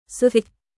Suỵt!スィッ！シーッ！（静かにの合図）